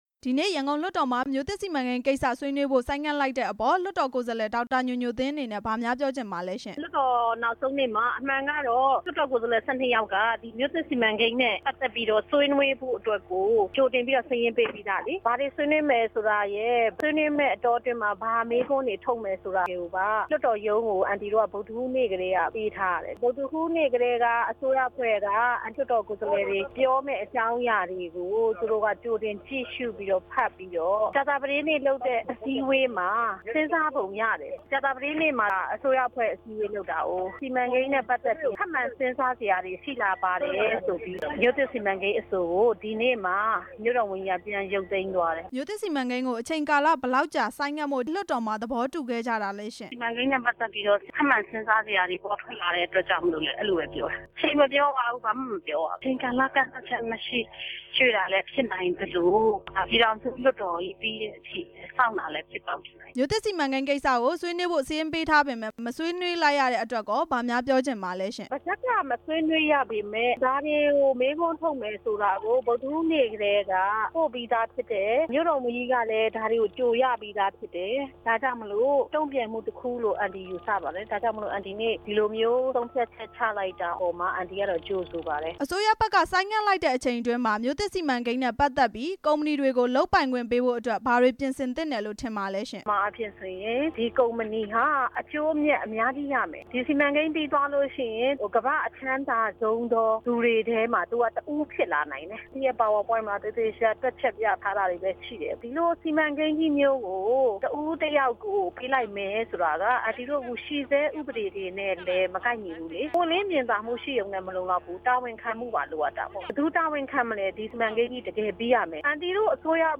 ဒေါက်တာ ညိုညိုသင်းနဲ့ မေးမြန်းချက်